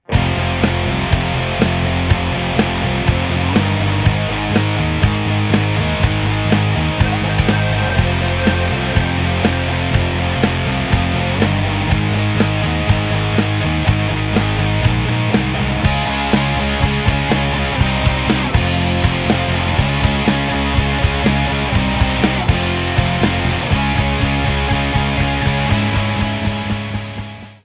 Recueil pour Percussions